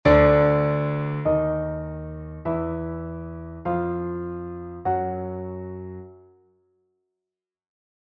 der Ton Eses, Notation
Es-es [heutige Schreibweise: Eses], Silbenname desjenigen Tones, der als mittels des Doppel-B (♭♭) (siehe Notenschrift) vollzogene doppelte Erniedrigung des Tones E um zwei halbe Töne oder als nochmalige Erniedrigung des schon erniedrigten Tones Es um einen zweiten halben Ton erscheint und auf gleichschwebend temperierten Instrumenten mit dem Ton D auf einer Taste zusammenfällt.
der-Ton-Eses.mp3